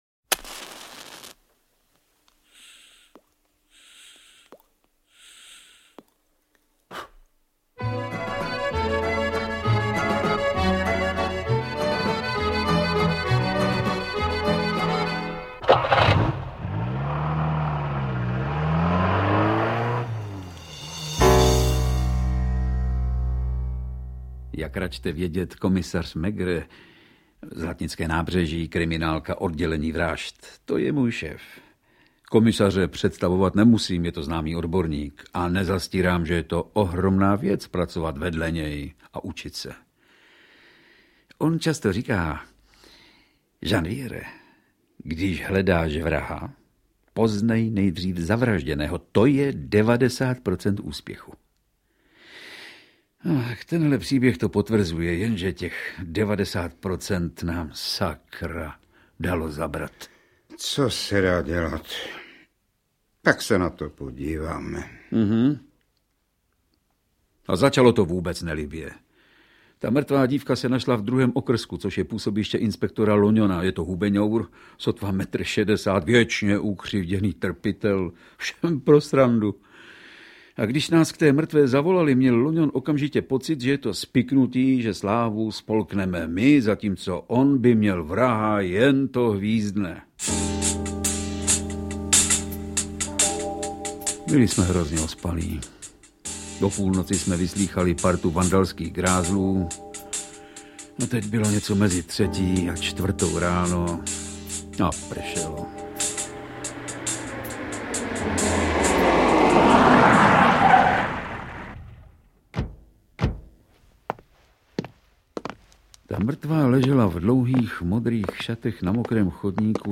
Interpreti:  Jiří Adamíra, Jaroslava Adamová, Blanka Bohdanová, Vladimír Brabec, Vlastimil Hašek, Rudolf Hrušínský, Jorga Kotrbová, Věra Kubánková, Ilja Racek, Karolína Slunéčková, Miluše Šplechtová, Jiří Vala